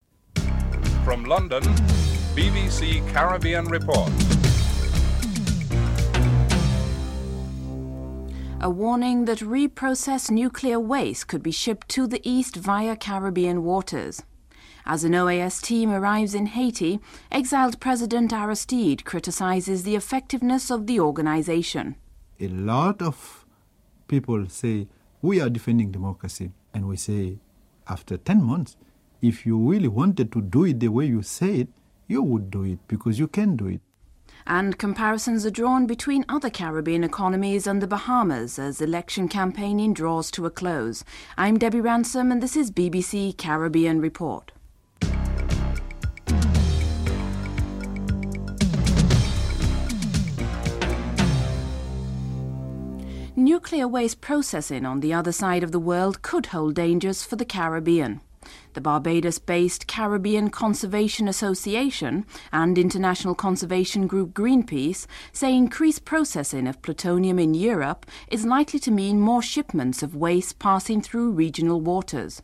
The British Broadcasting Corporation
1. Headlines (00:00-00:52)